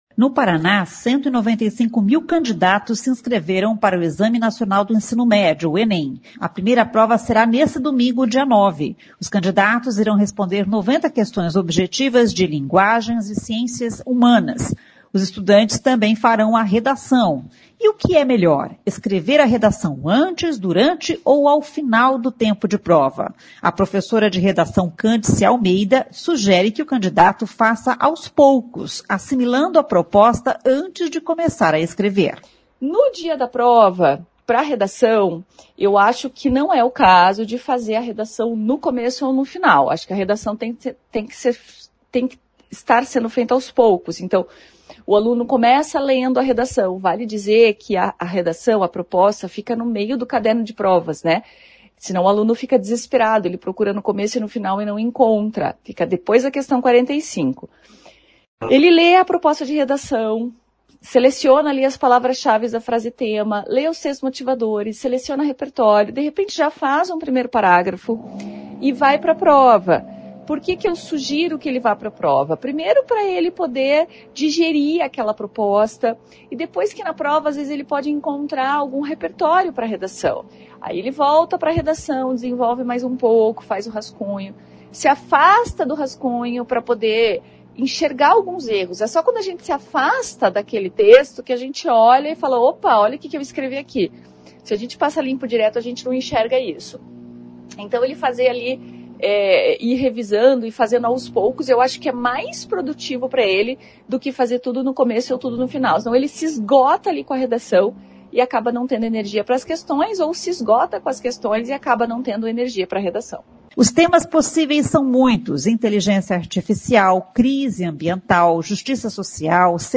Professores dão dicas de estudo e redação para a prova do Enem